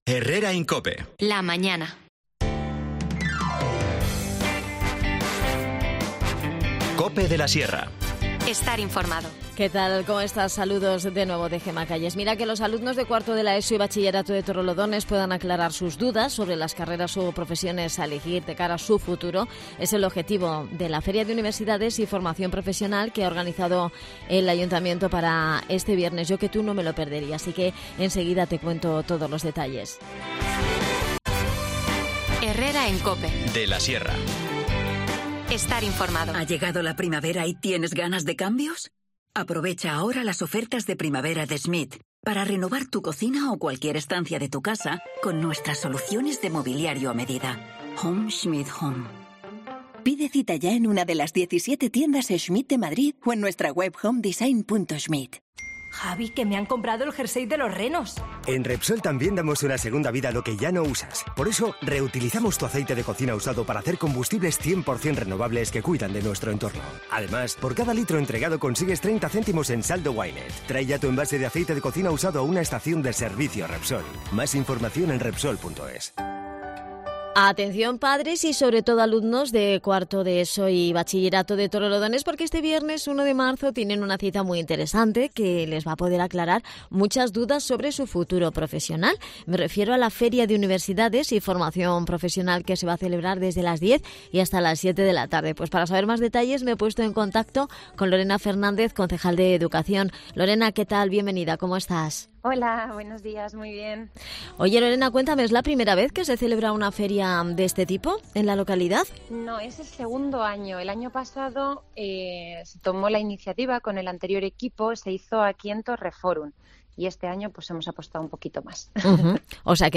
Nos cuenta todos los detalles y lo que hay que hacer para asistir, Lorena Fernández, concejal de Educación.
Y si quieres saber qué es el Poetry Slam Torrelodones que se va a celebrar este viernes 1 de marzo en la Zona Joven de Torreforum a las 19:30 horas también te lo contamos en este espacio con Manuela Sánchez, concejal de Juventud.